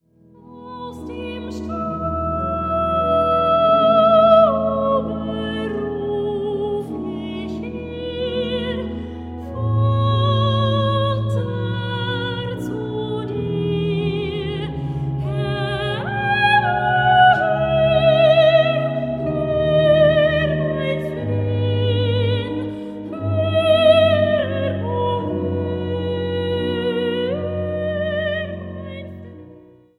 für fünf Männerstimmen